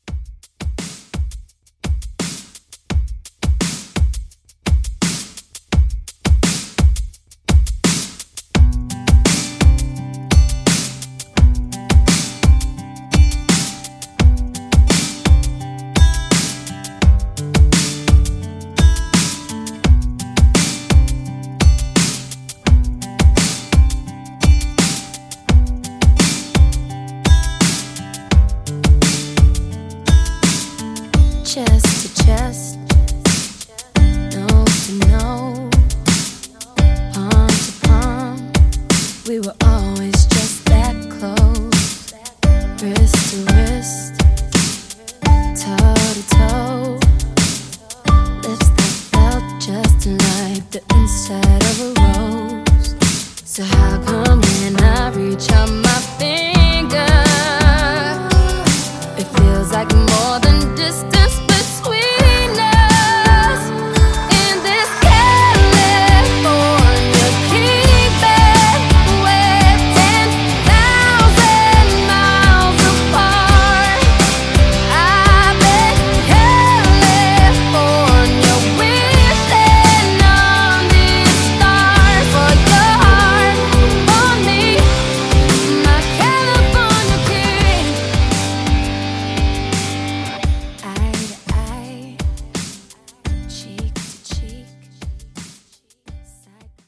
Genre: MASHUPS Version: Clean BPM: 102 Time